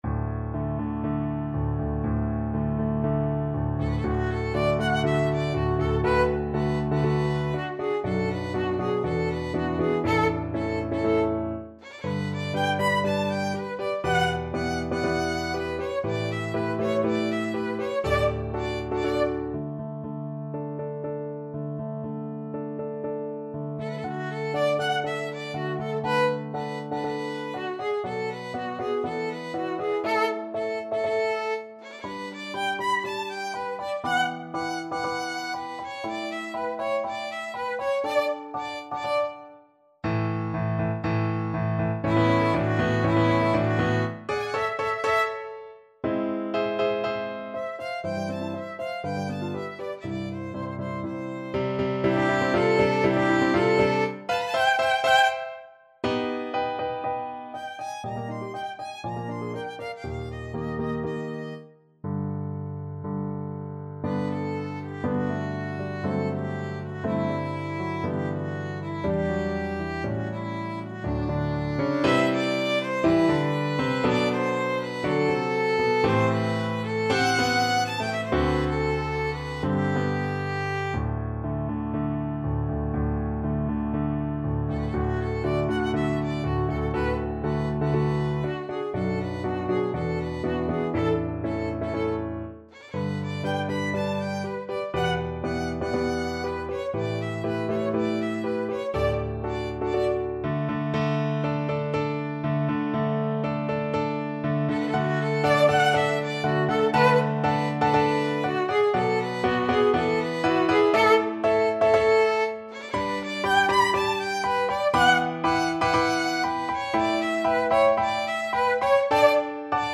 Free Sheet music for Violin
4/4 (View more 4/4 Music)
~ = 120 Tempo di Marcia un poco vivace
D major (Sounding Pitch) (View more D major Music for Violin )
D5-C7
Violin  (View more Intermediate Violin Music)
Classical (View more Classical Violin Music)